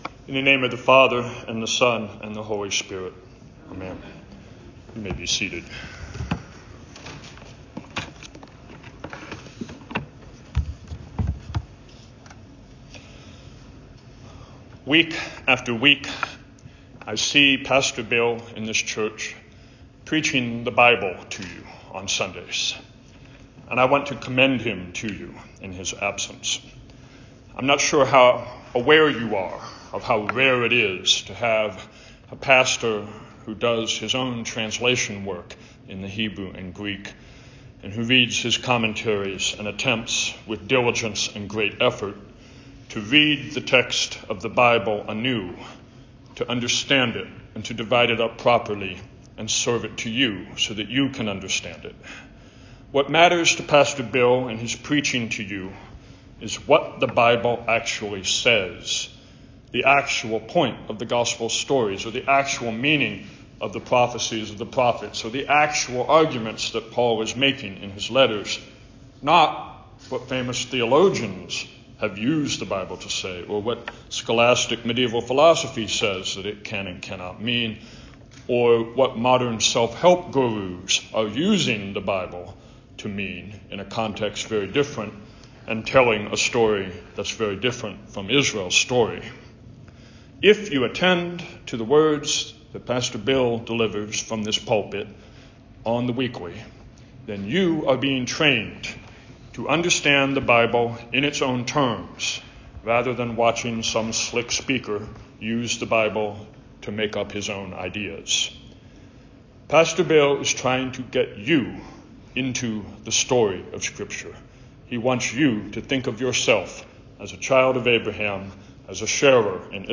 A Sermon for the First Sunday in Lent